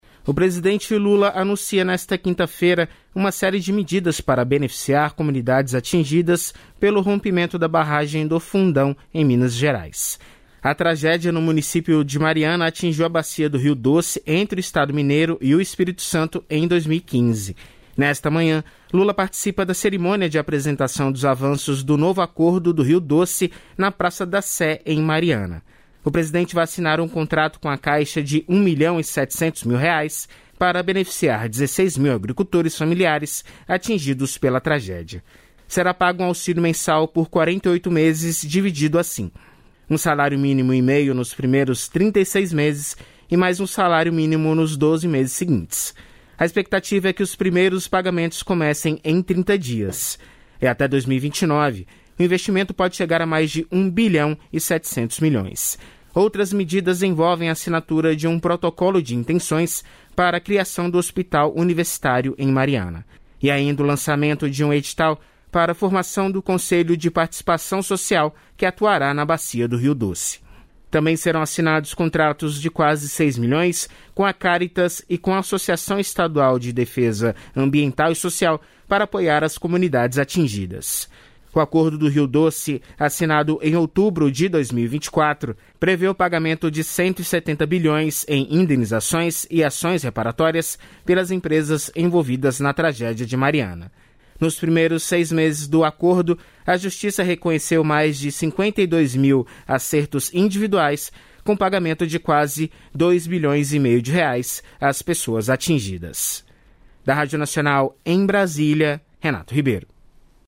* A participação da repórter foi ao vivo.